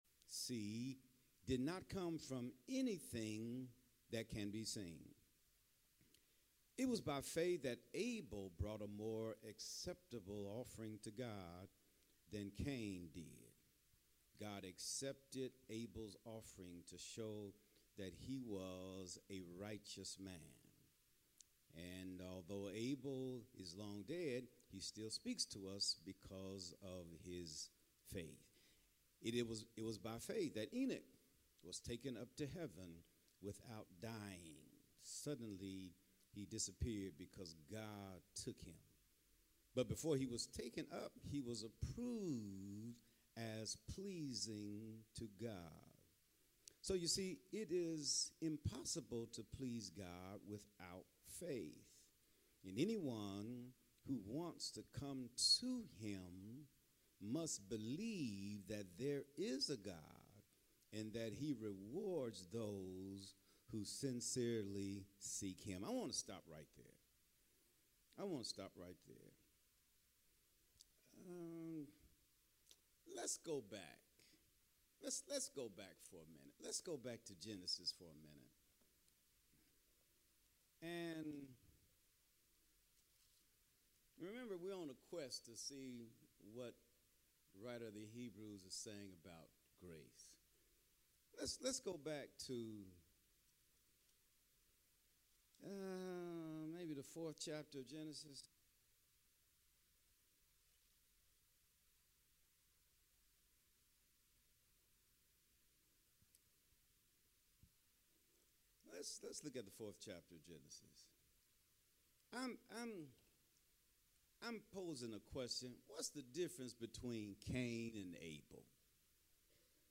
Sunday-School-1-11-26.mp3